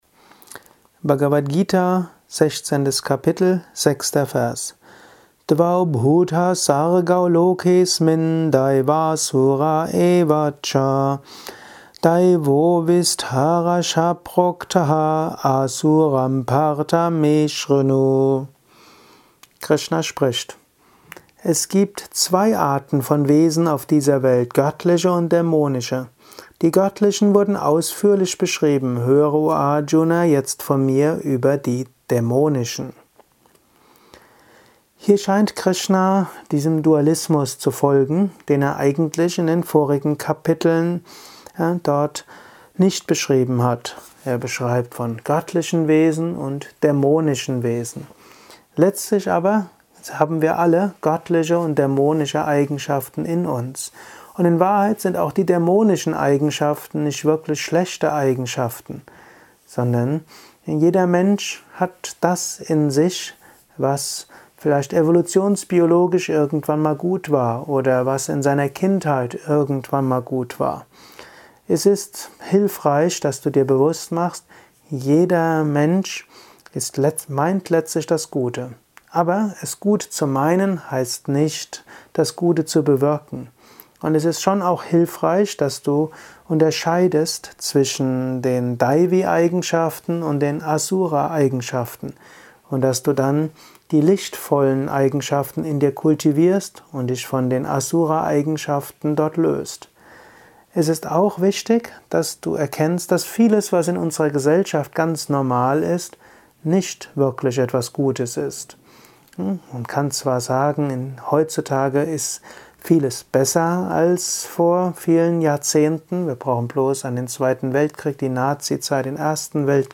Dies ist ein kurzer Kommentar